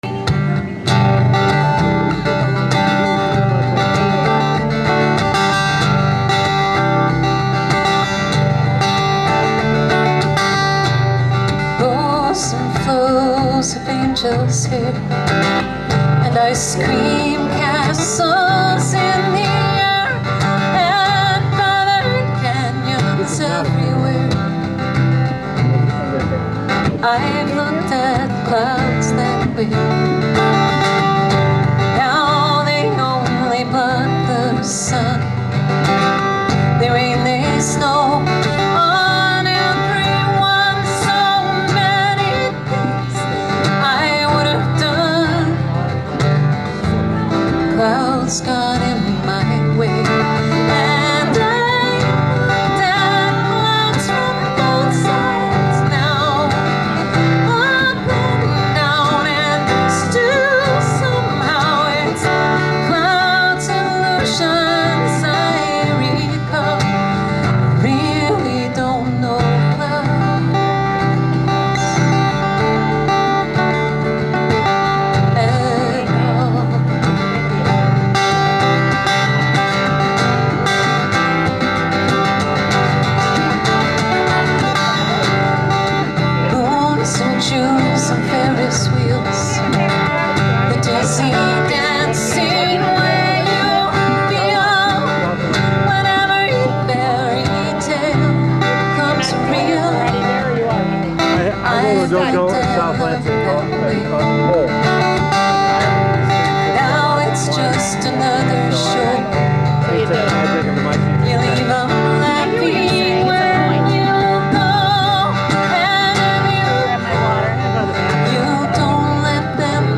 on percussion